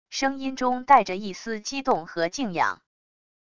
声音中带着一丝激动和敬仰wav音频